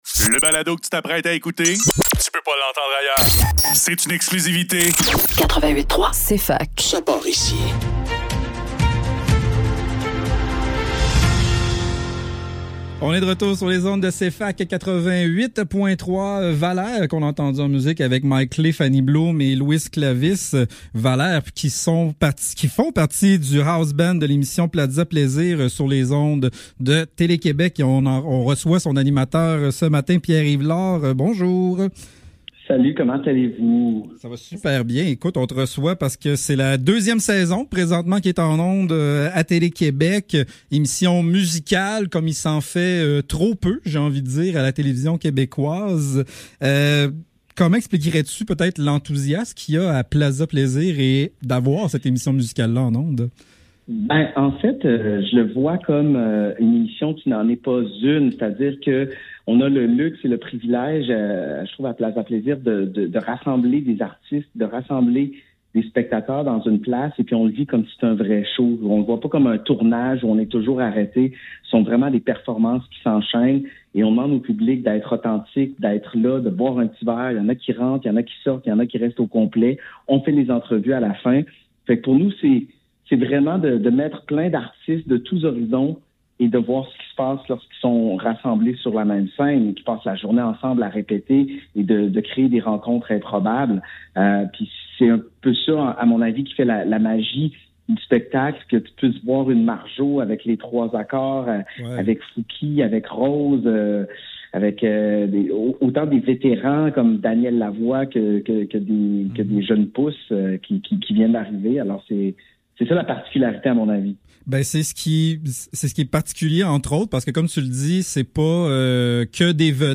Le Neuf - Entrevue : Pierre-Yves Lord - Plaza Plaisir (Télé-Québec) - 18 février 2025